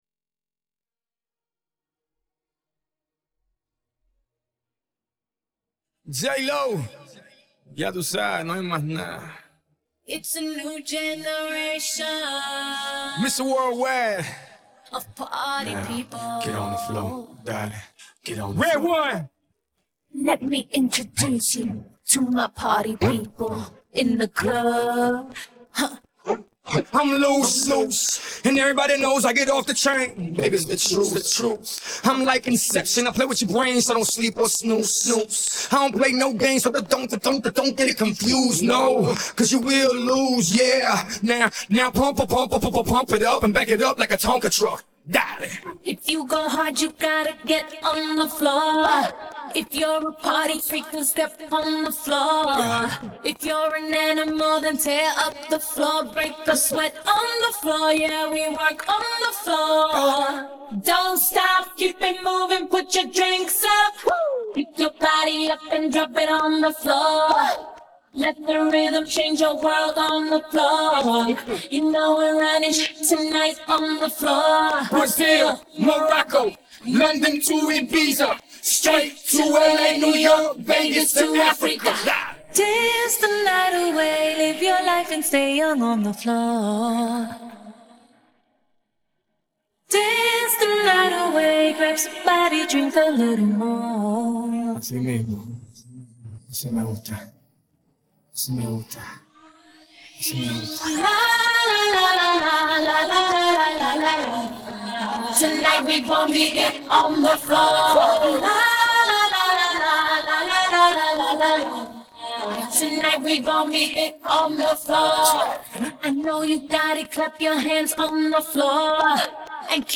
Голосовая часть